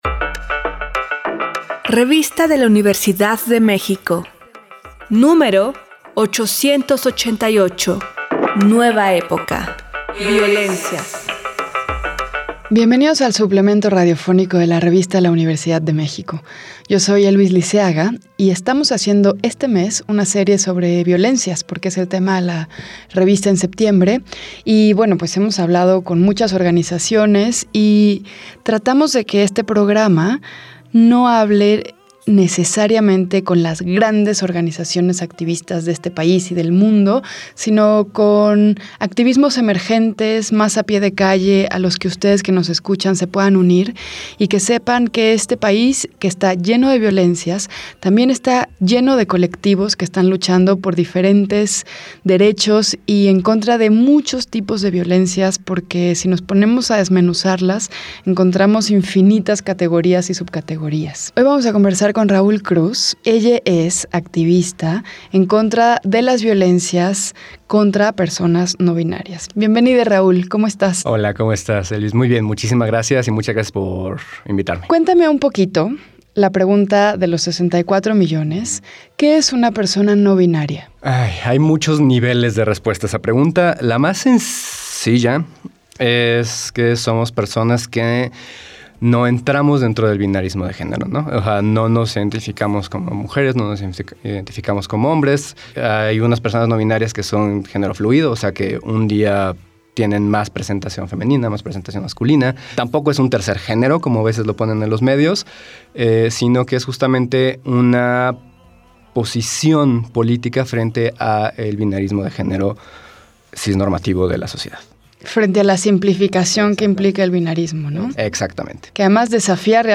Fue transmitido el jueves 22 de septiembre de 2022 por el 96.1 FM.